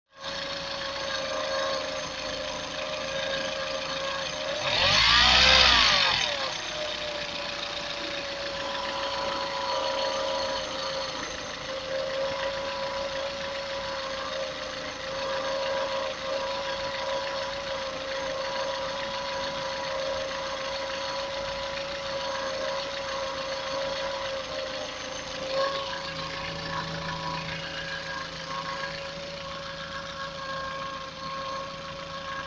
Помогите определить, что воет, на слух по аудиозаписи.
Еле еле нашел более-менее подходящий диктофон для телефона.
Вой постоянный, в последние 20 тысяч немного усилился. кпп отпадает, не оно.
Звук вроде как со стороны ремней.